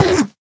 sounds / mob / endermen / hit2.ogg
hit2.ogg